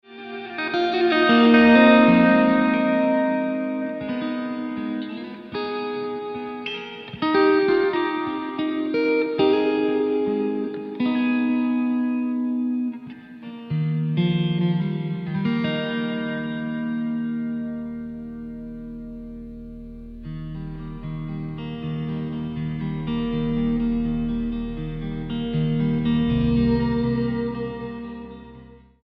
STYLE: Rock
ventures into ambient territory